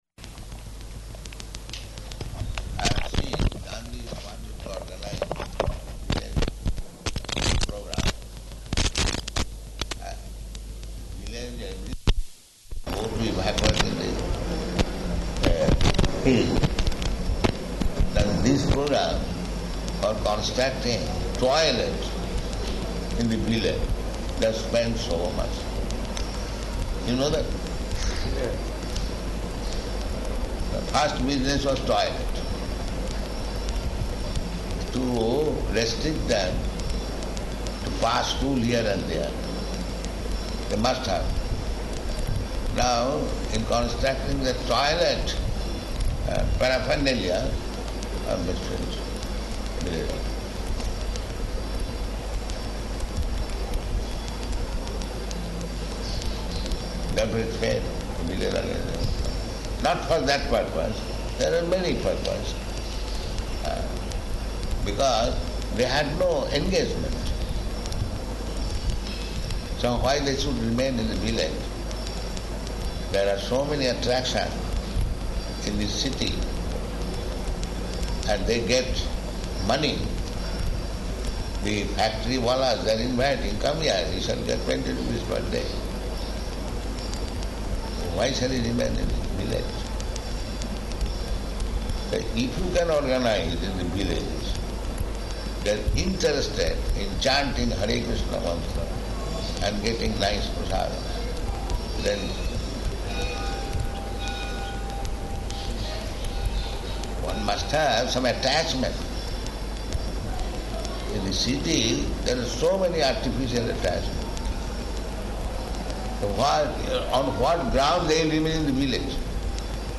Type: Conversation
Location: Vṛndāvana